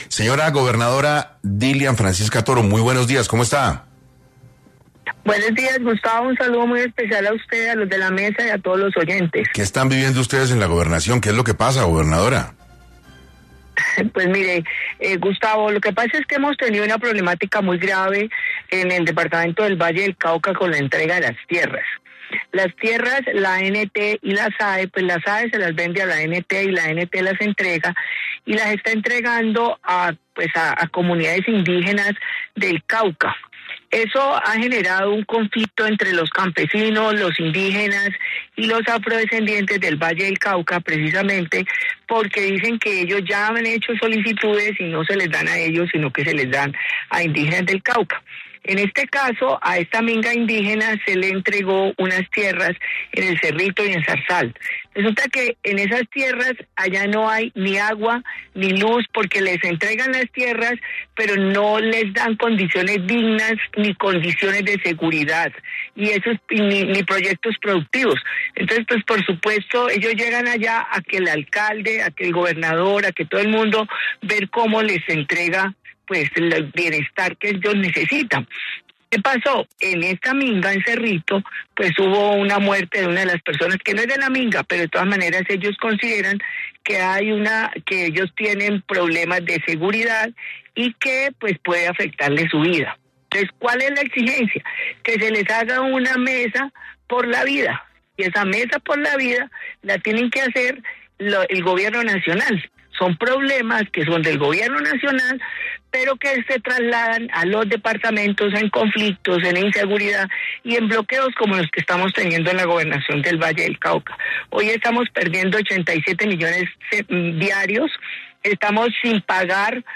En entrevista con 6AM de Caracol Radio, la Gobernadora del Valle, Dilian Francisca Toro, dijo que hay una problemática muy grave con la entrega de las tierras, la Agencia Nacional de Tierras (ANT) las está entregando a comunidades indígenas del Cauca, lo que ha generado un conflicto entre los campesinos.